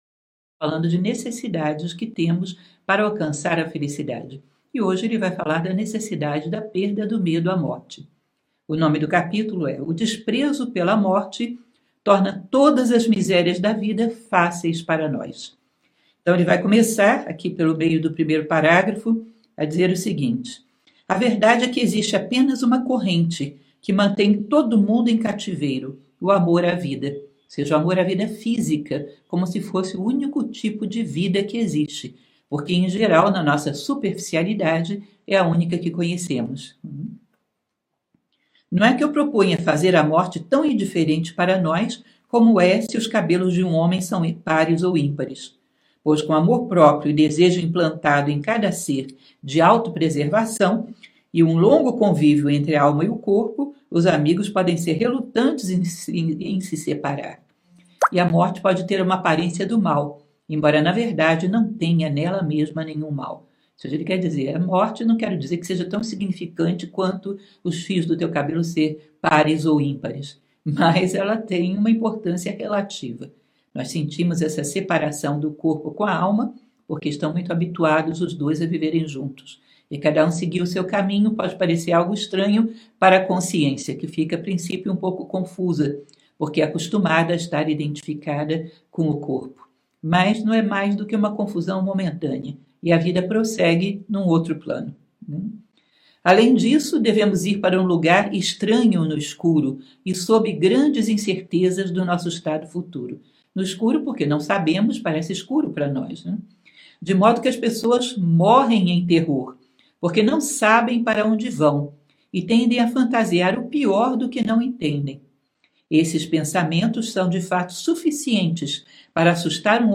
Leitura comentada do 21º capítulo